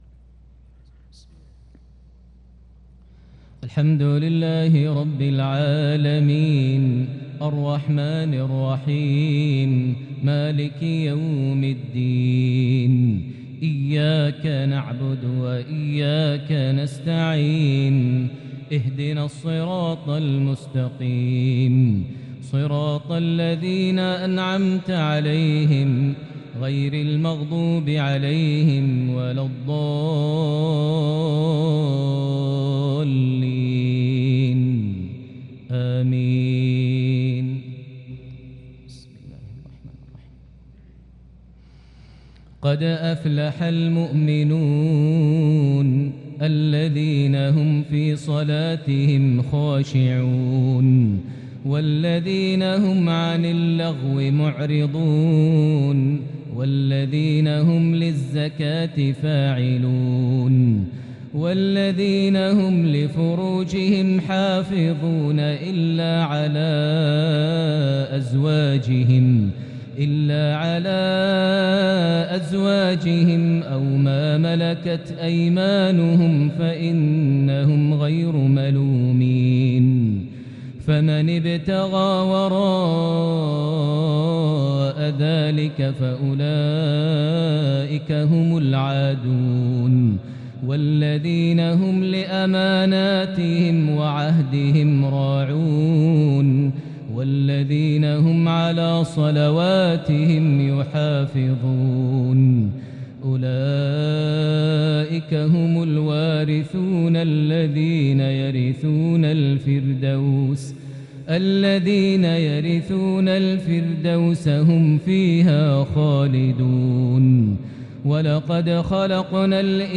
عشائية بديعة للغاية لفواتح سورة المؤمنون 1-22 | الاثنين 12 ربيع الأول 1443هـ > 1443 هـ > الفروض - تلاوات ماهر المعيقلي